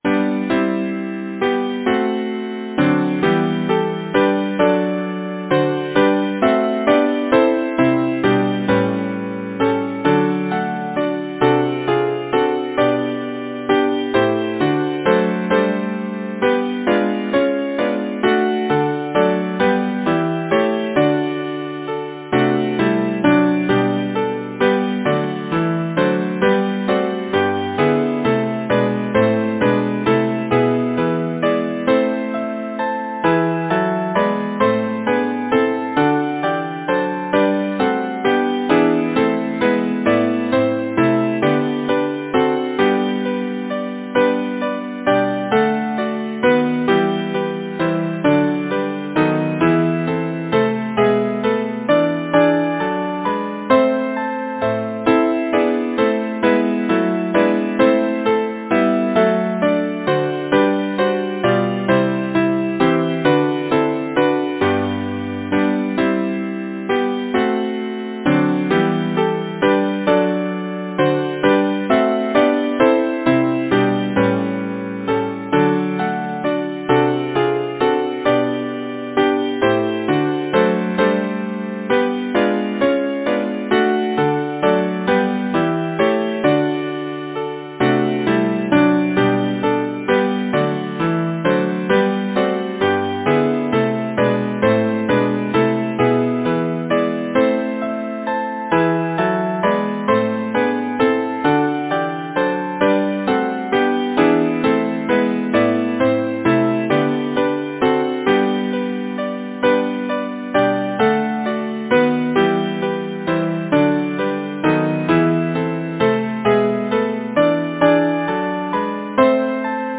Title: Behold! the Moon Composer: Edward Bunnett Lyricist: Edward Oxenford Number of voices: 4vv Voicing: SATB Genre: Secular, Partsong
Language: English Instruments: Piano